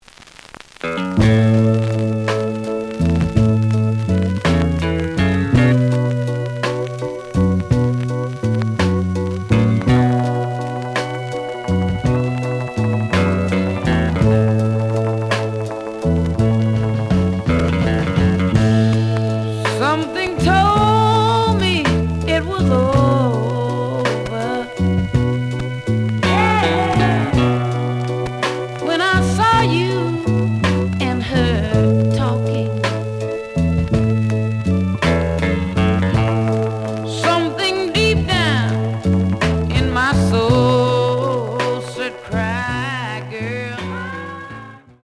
The audio sample is recorded from the actual item.
●Genre: Soul, 60's Soul
Some noticeable periodic noise on beginning of B side.